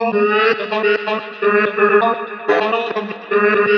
• chopped vocals ping pong house delayed - G# - 119.wav
chopped_vocals_ping_pong_house_delayed__-_G_sharp__-_119_4oE.wav